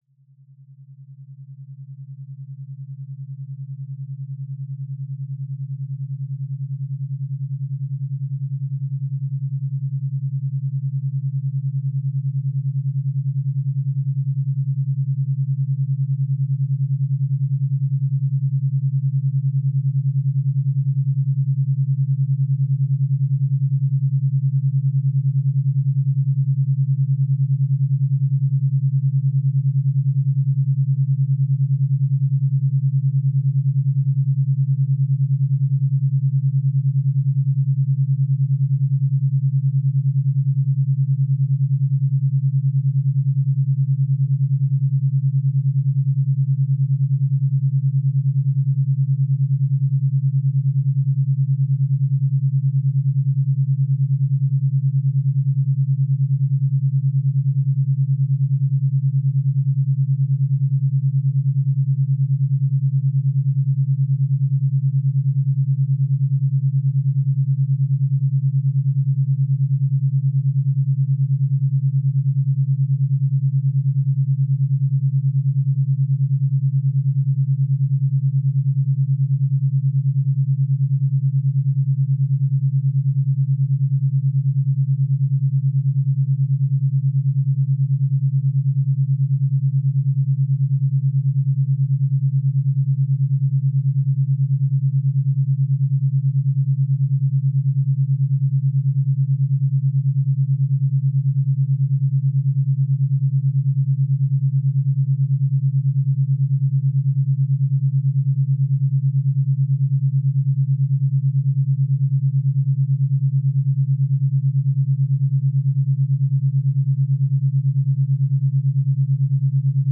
Alpha brainwaves are associated with a relaxed, meditative, dream-like state and are between 8 Hz and 12 Hz as measured by EEG. Nada Brahma uses binaural beats to increase alpha brainwaves. The right and left channels start out with slightly different frequencies, 10 Hz apart (the Berger Rhythm).
The base frequency used is 136.1 Hz, the sound of Om.